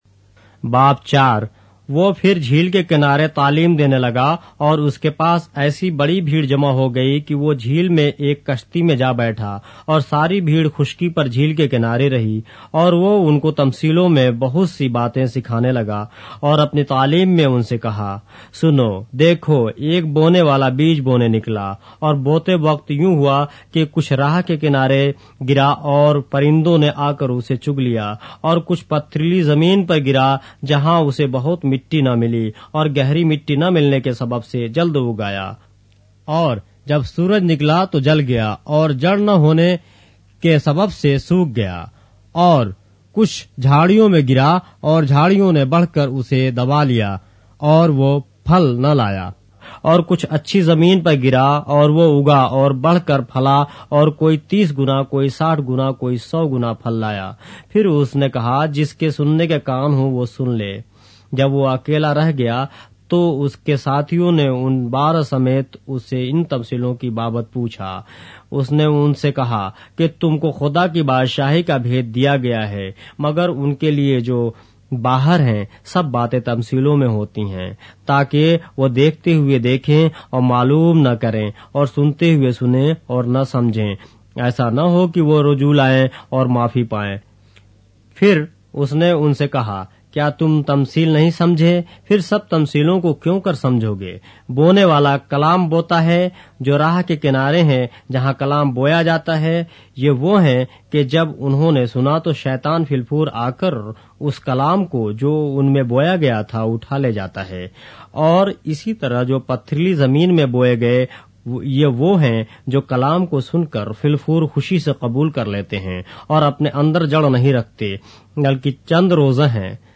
اردو بائبل کے باب - آڈیو روایت کے ساتھ - Mark, chapter 4 of the Holy Bible in Urdu